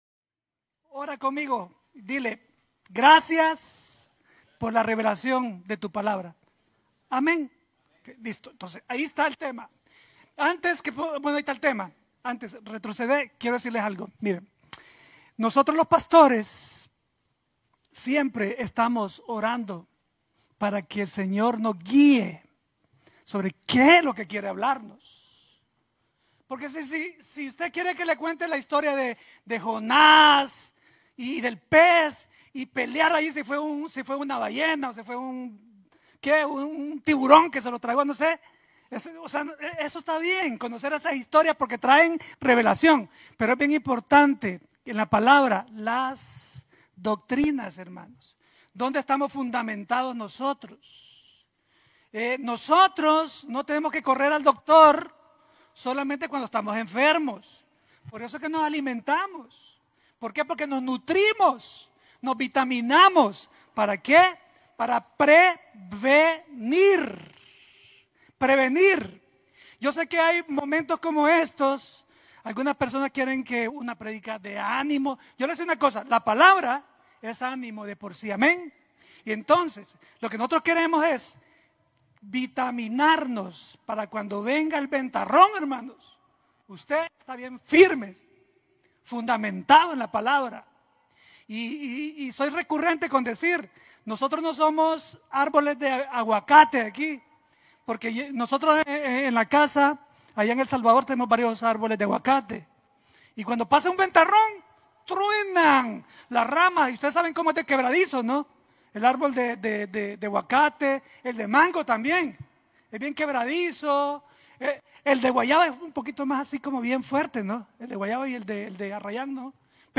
predica doctrina desde Chicago, Illinois